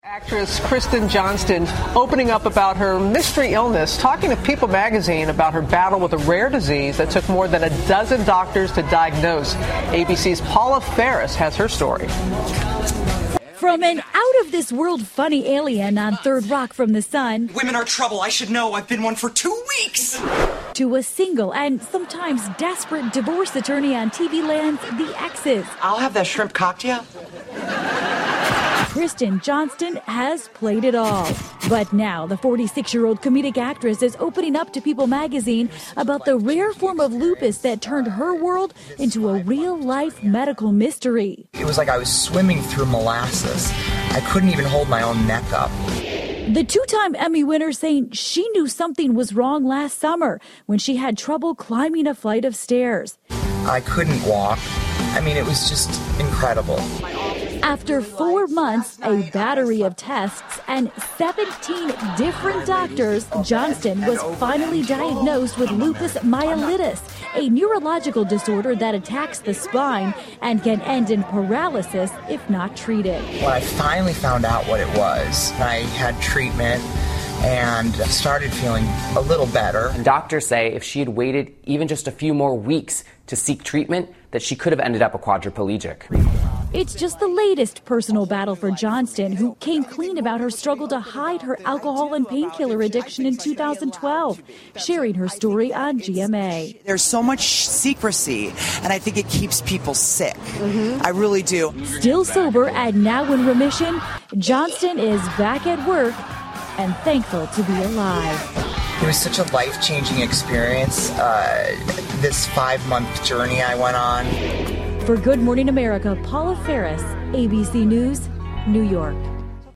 访谈录